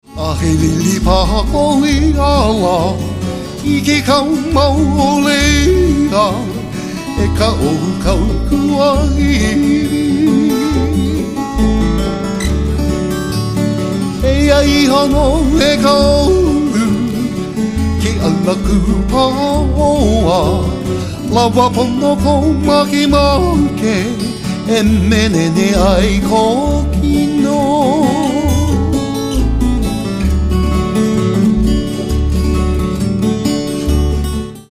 • Genre: Traditional Hawaiian.
background vocals, slack-key
steel guitar